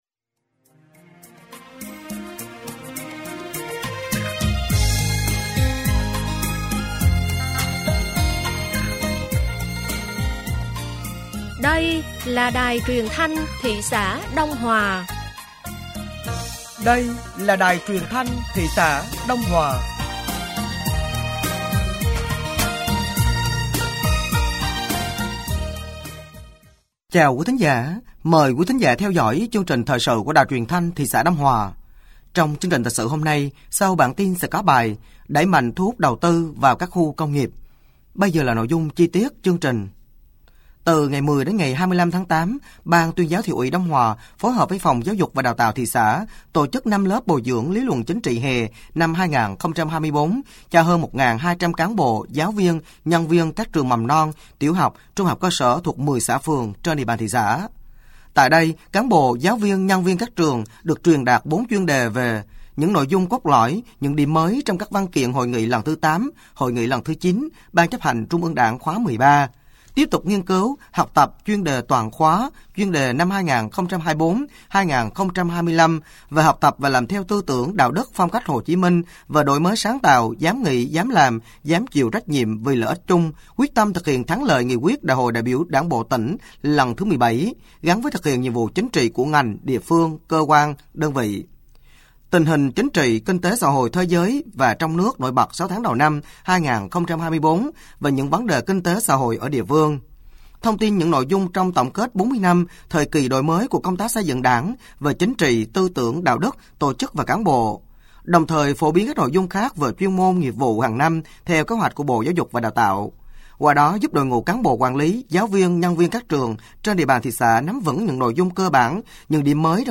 Thời sự tối ngày 26 và sáng ngày 27 tháng 8 năm 2024